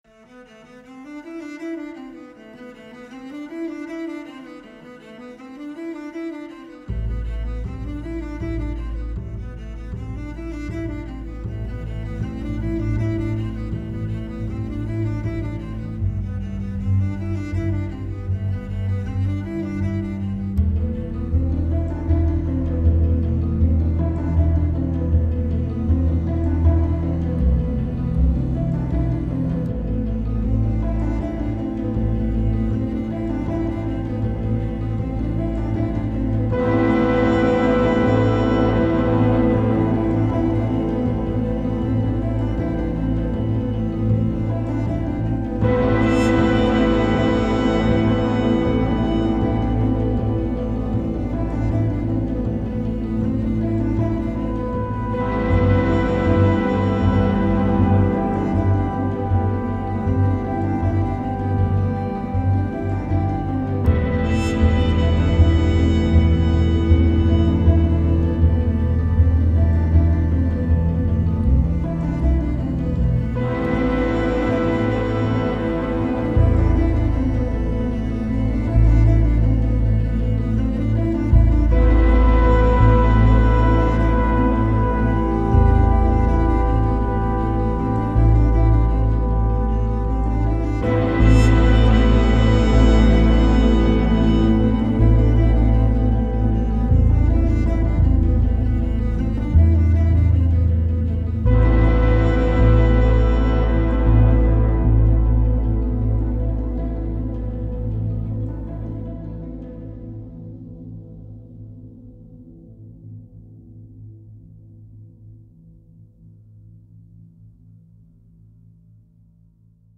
merveilles symphoniques à cordes